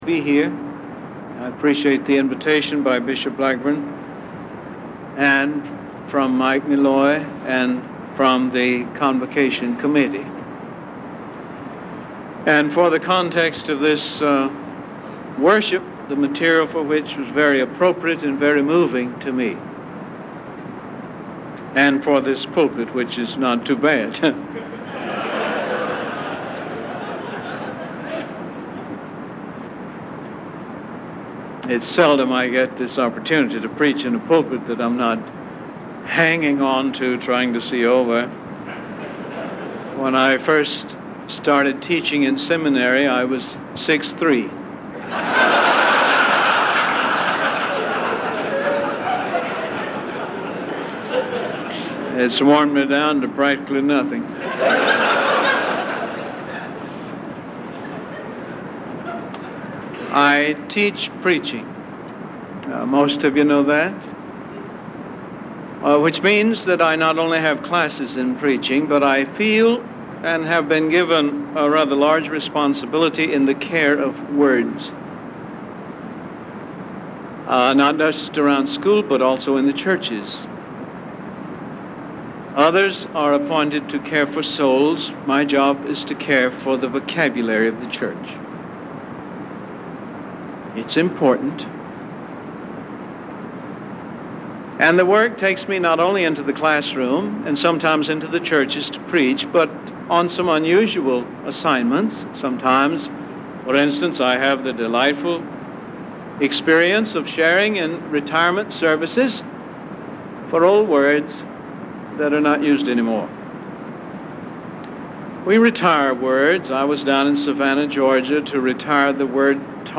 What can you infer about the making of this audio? Virginia Pastors' Convocation 1988